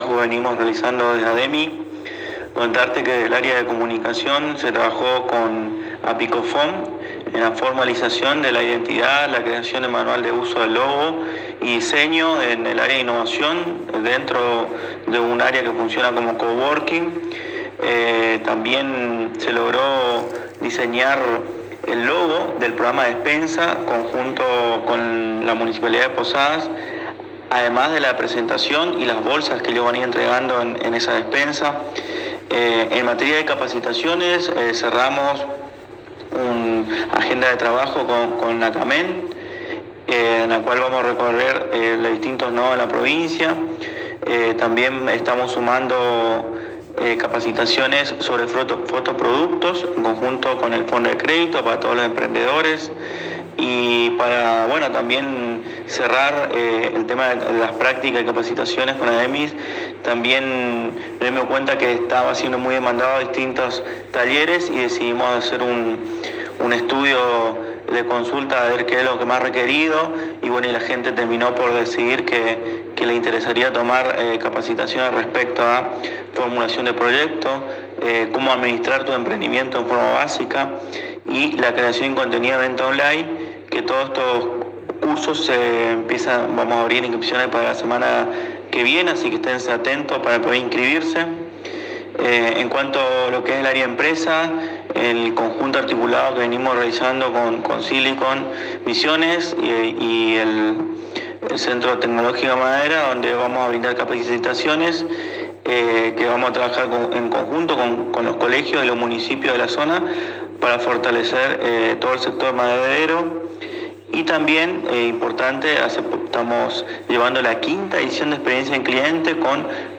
en diálogo exclusivo con la ANG